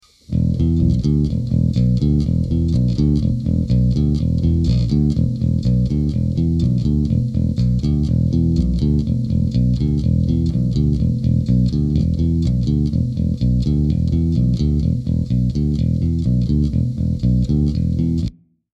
no sice jeto přes amplitube   nijak  mě to teda zatím neohromilo  ale zatím těžko soudit